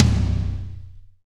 -DRY TOM 1-R.wav